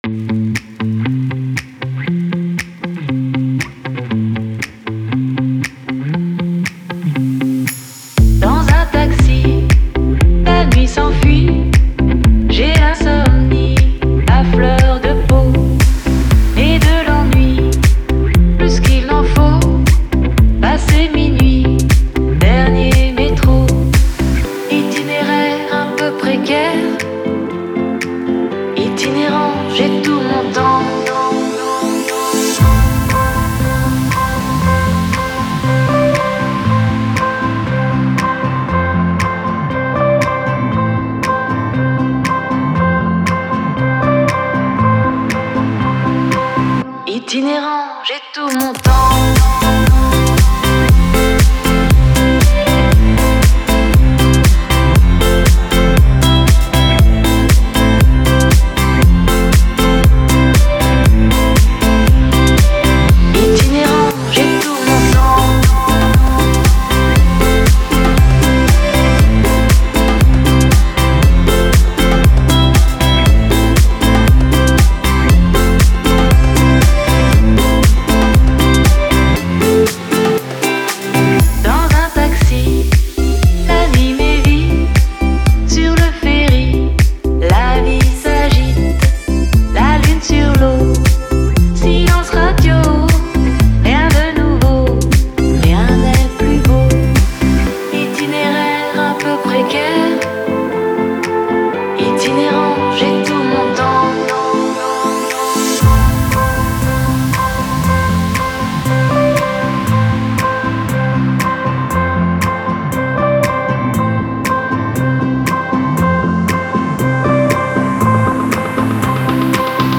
Стиль: Deep House / Dance / Pop